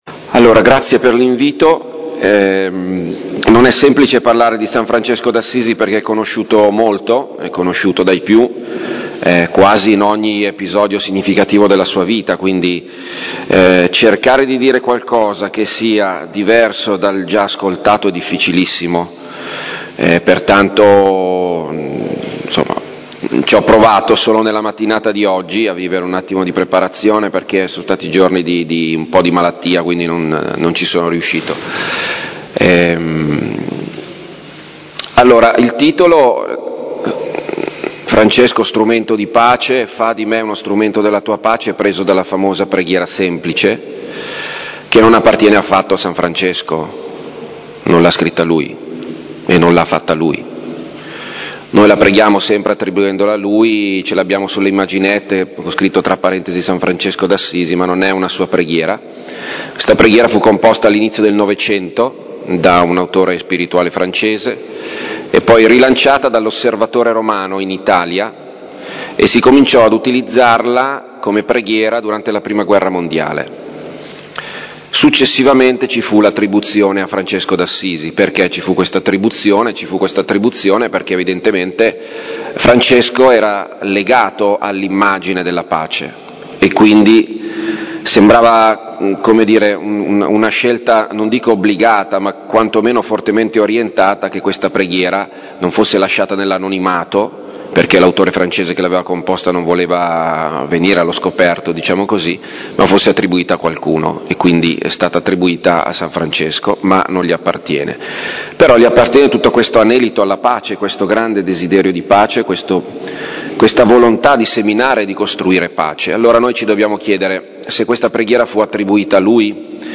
Lectio
presso auditorium San Giovanni a Fiorenzuola.